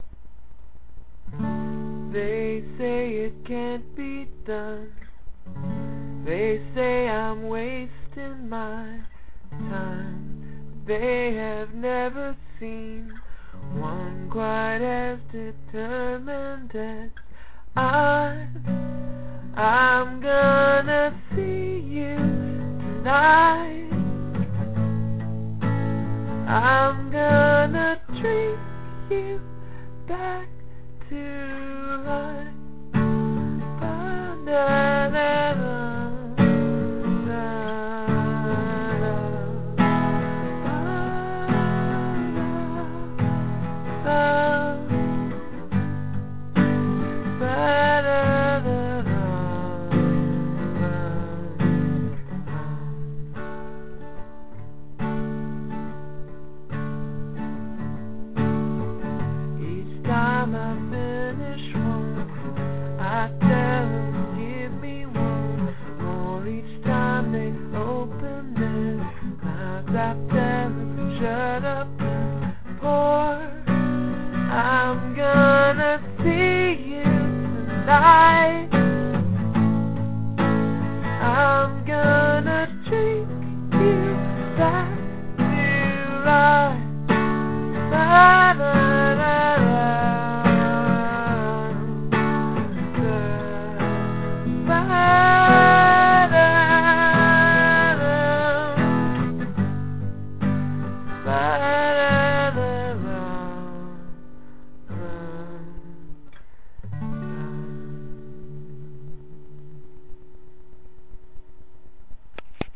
Drink You Back (roughest possible demo) - MP3
Isn't inherently country musically, but I think the subject matter qualifies it. Could use a piano. Could use a nice backbeat over the second verse?